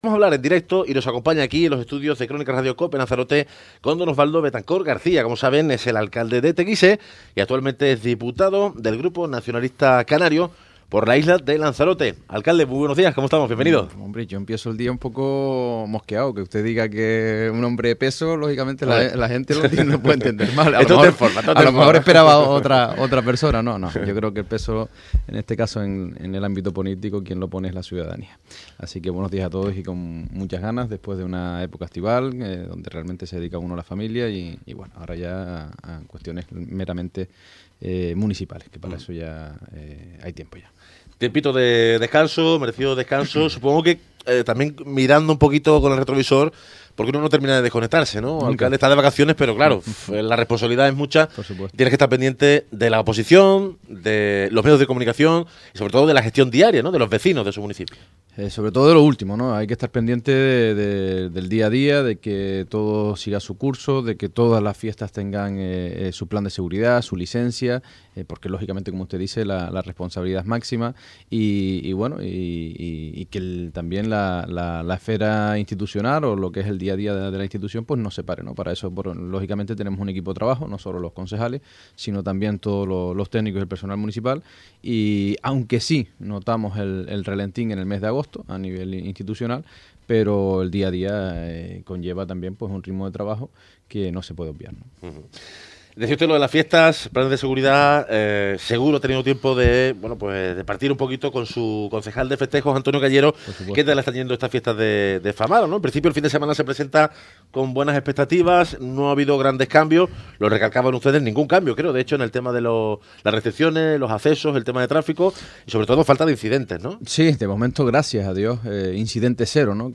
entrevista_oswaldo_betancort.mp3 El alcalde de Teguise y diputado regional del Grupo Nacionalista Canario por Lanzarote, Oswaldo Betancort, ha reaparecido este viernes ante la opinión pública en una extensa entrevista concedida al programa 'A buena hora', en los estudios de Crónicas Radio-COPE Lanzarote en la que, entre otras cosas, ha acusado al PSOE y a su portavoz en Teguise, Marcos Bergaz, de aprovechar la información como ex responsable de la redacción del Avance del Plan Insular de Ordenación de Lanzarote (PIOL) para desempeñar su papel en la oposición municipal.
entrevista_oswaldo_betancort.mp3